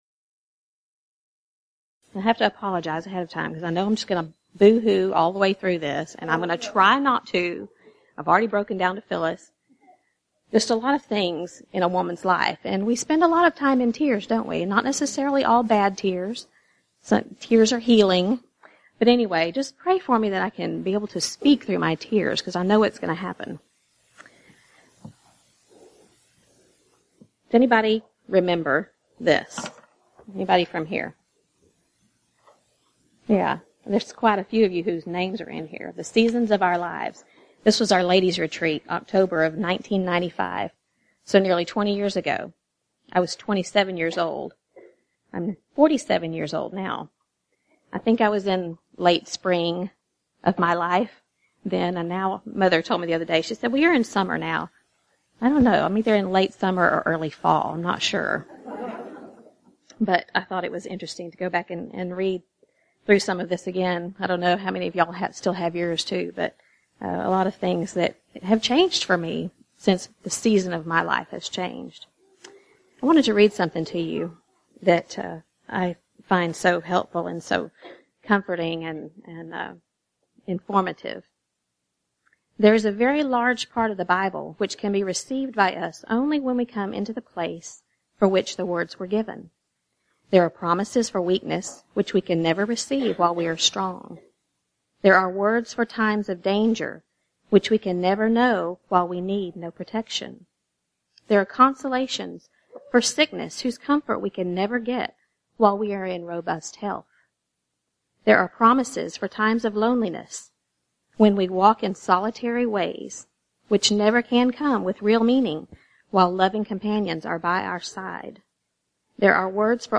Event: 34th Annual Southwest Lectures Theme/Title: God's Help with Life's Struggles
Ladies Sessions